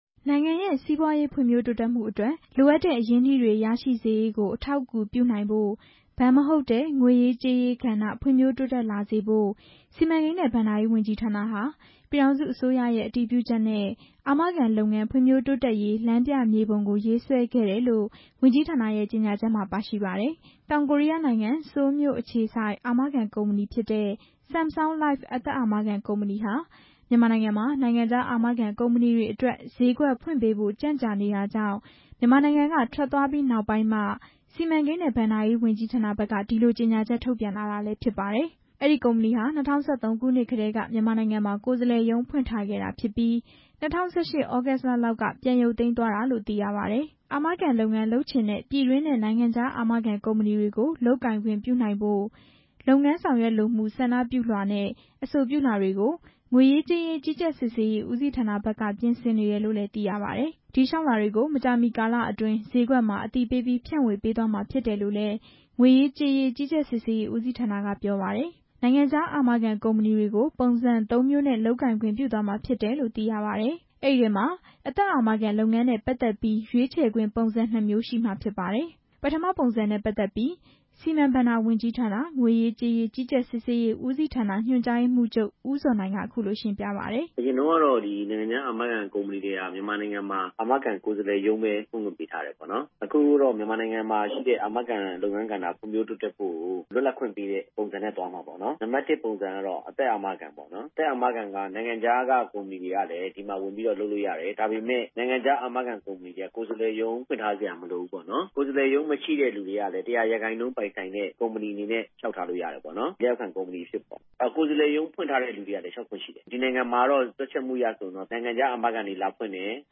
ဒီအကြောင်းနဲ့ပတ်သက်ပြီး ဝန်ကြီးဌာနတာဝန်ရှိသူတွေ၊ စီးပွားရေးပညာရှင်တွေကို မေးမြန်းပြီး